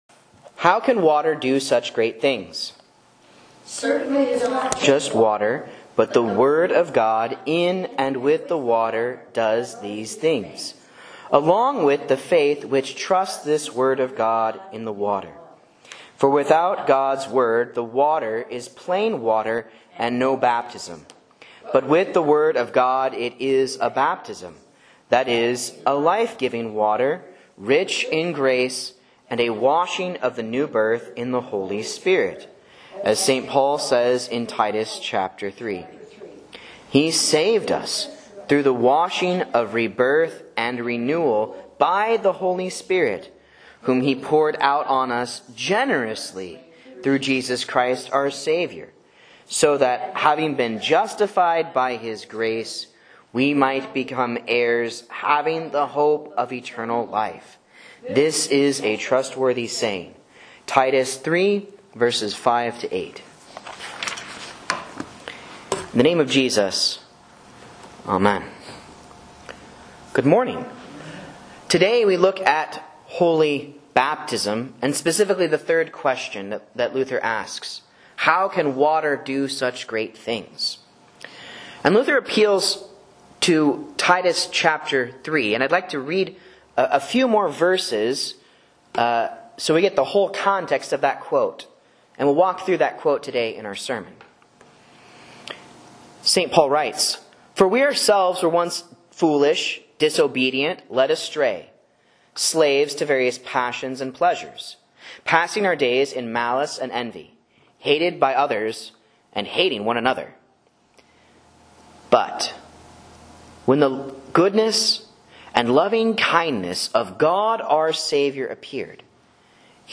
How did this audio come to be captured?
Sermon and Bible Class Audio from Faith Lutheran Church, Rogue River, OR